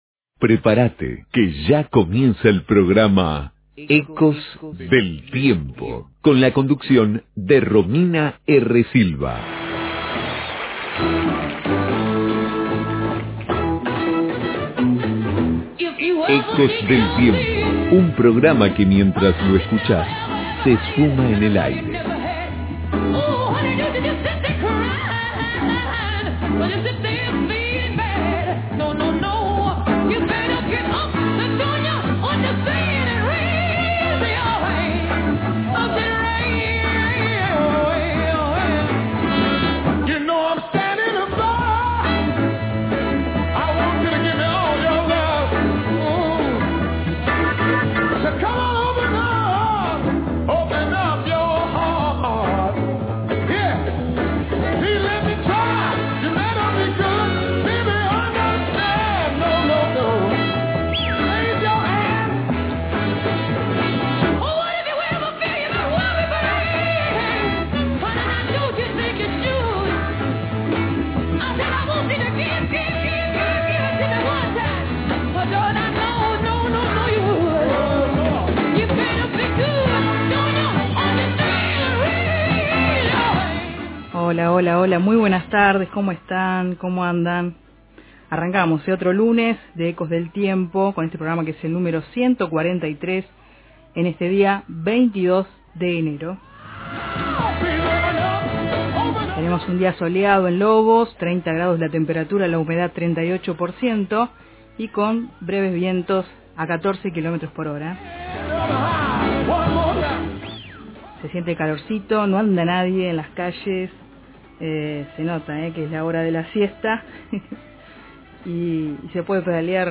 En vivo charlamos